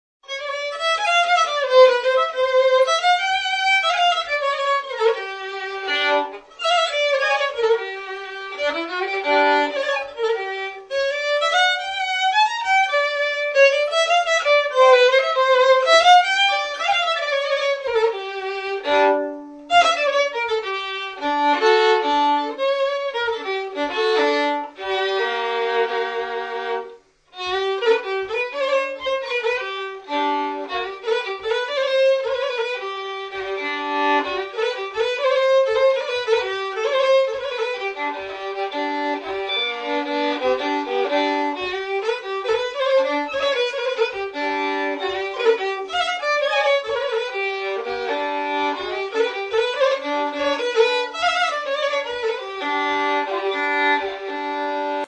Authentic Hutsul Music From the Ukrainian Carpathians